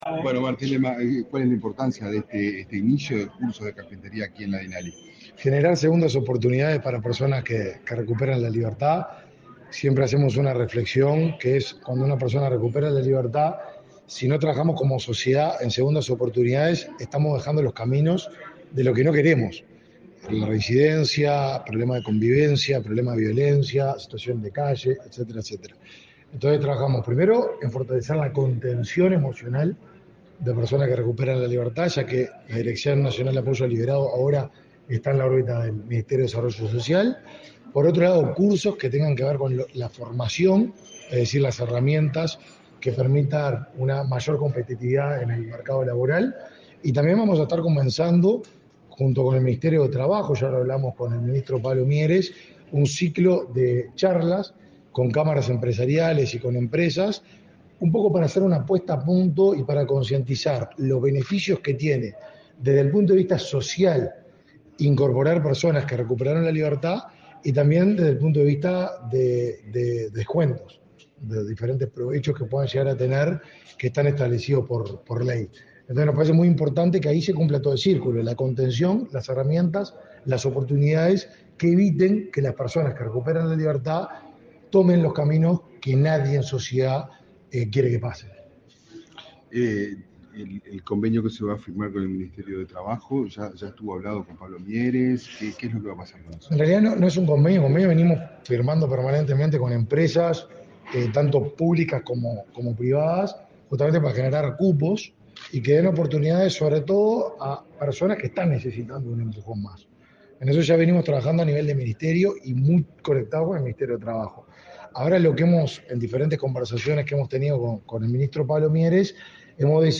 Declaraciones a la prensa del ministro de Desarrollo Social, Martín Lema
Declaraciones a la prensa del ministro de Desarrollo Social, Martín Lema 13/02/2023 Compartir Facebook X Copiar enlace WhatsApp LinkedIn Tras el inicio de los cursos de carpintería en talleres de la Dirección Nacional del Liberado (Dinali), este 13 de febrero, el ministro de Desarrollo Social, Martín Lema, realizó declaraciones a la prensa.